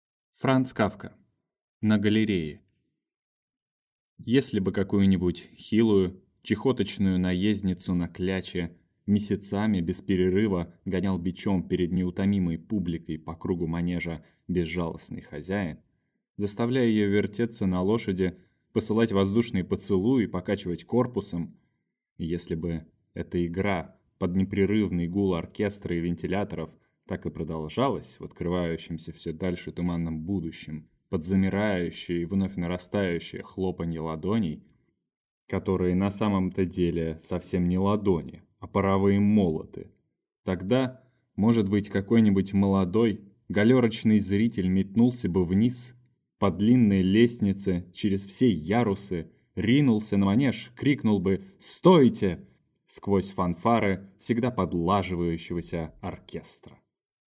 Aудиокнига На галерее